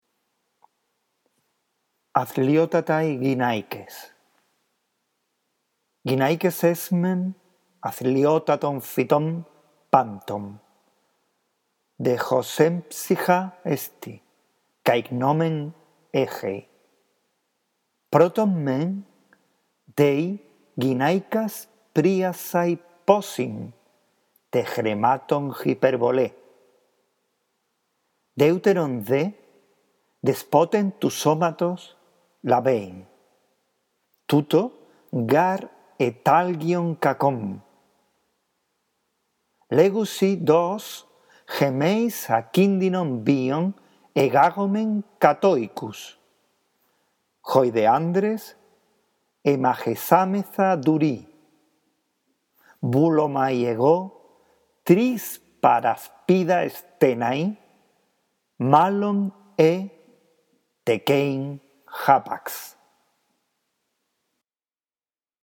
La audición de este archivo te guiará en la lectura y comprensión del texto